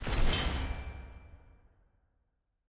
sfx_ui_research_kingdom.ogg